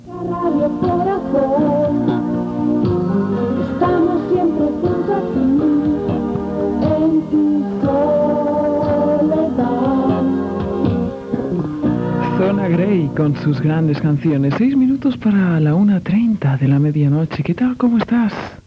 1f2d4b3a19b38ec17beed165de5dbbbe38fd88f9.mp3 Títol Radio Corazón Emissora Radio Corazón Titularitat Privada local Descripció Indicatiu, hora i tema musical.
Banda FM